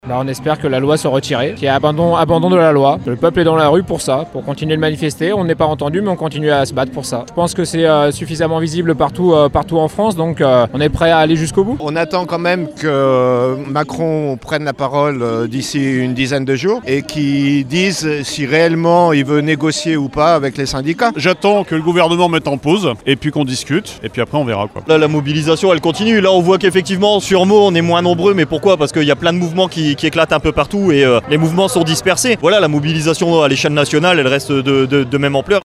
Ecoutez-les ce matin à Meaux devant la mairie…